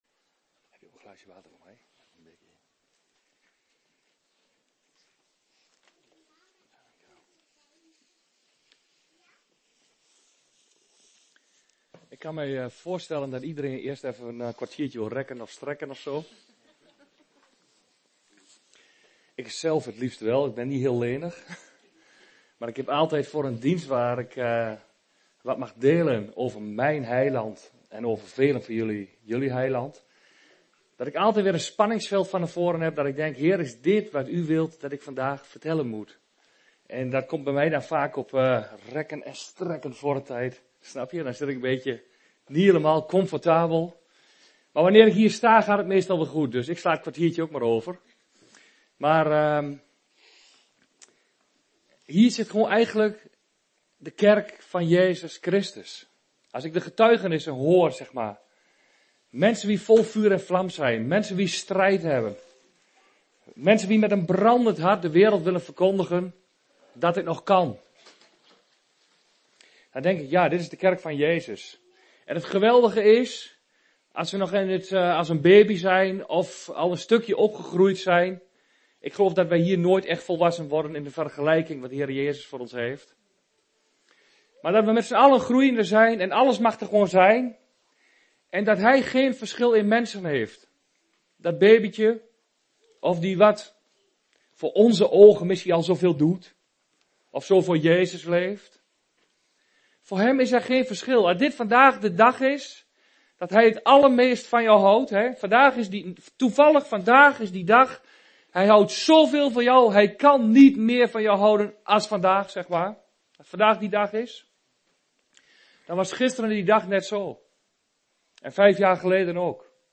Een preek over 'Wanneer geloof je dan?'.